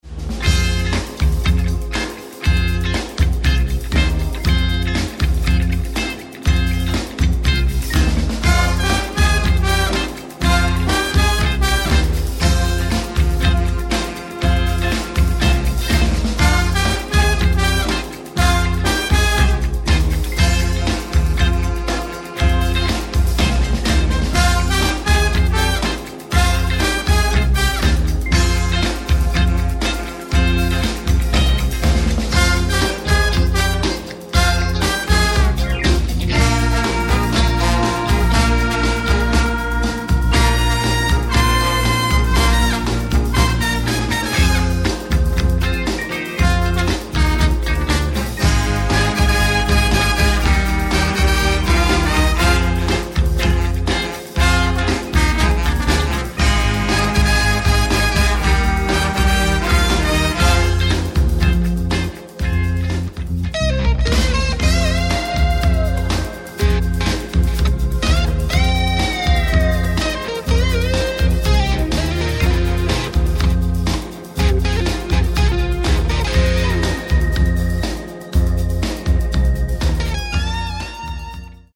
Street Band